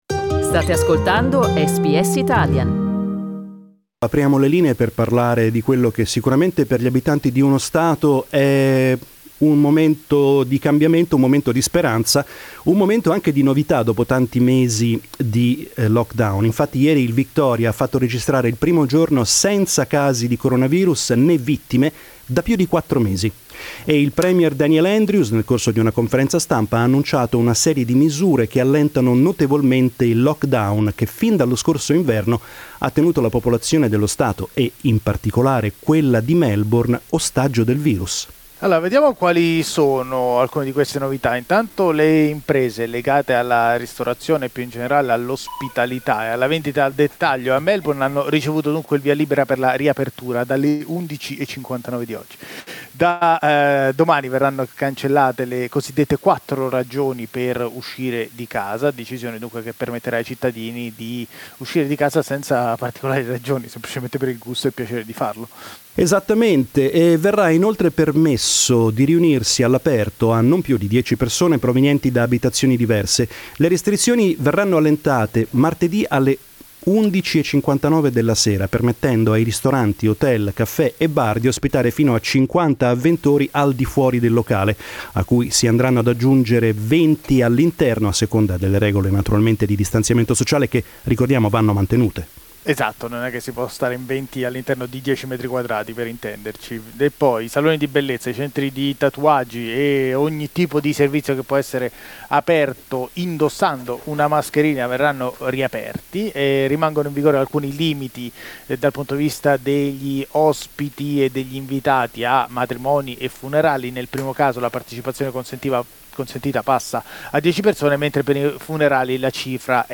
During our talkback listeners expressed their feelings and told us what they intend to do now that they feel more... free.